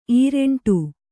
♪ īreṇṭu